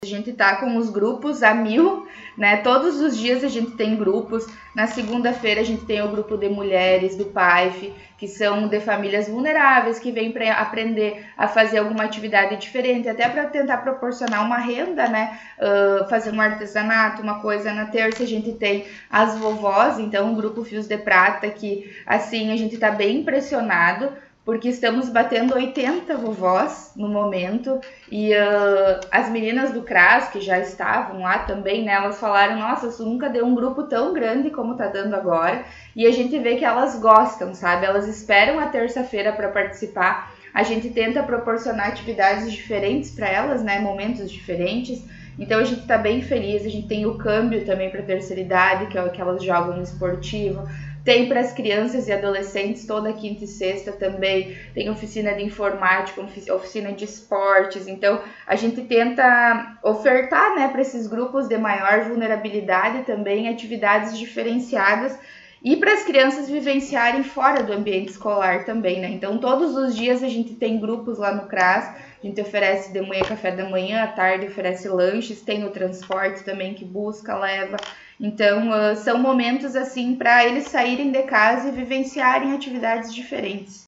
Secretária Municipal, Suelen Castro, concedeu entrevista